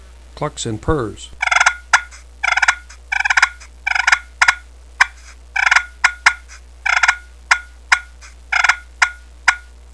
Listen to 10 seconds of clucks & purrs
perfpushpincluckspurrs10.wav